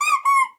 squeaky_rubber_toy_cartoon_04.wav